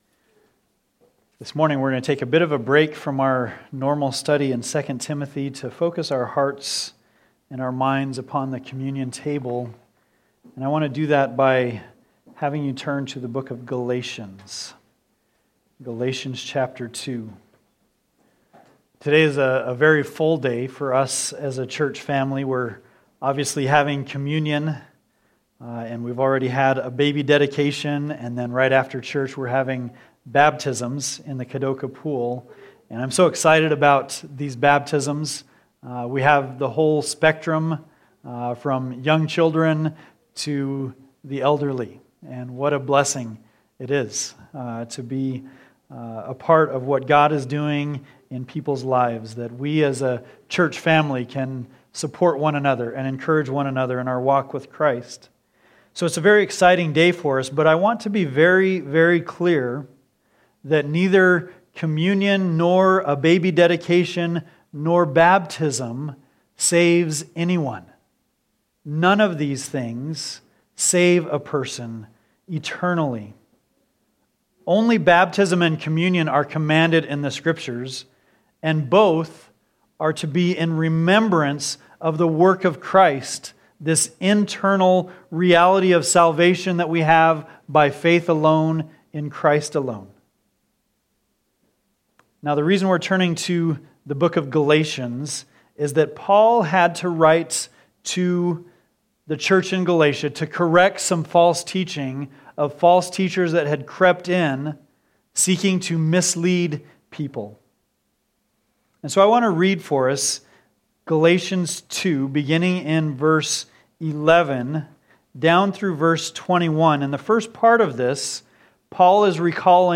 Communion Meditation Passage: Galatians 2:15-21 Topics